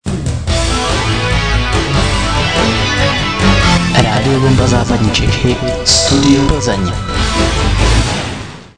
Jingl "krßtk²" 60 KB MP3-128 kbps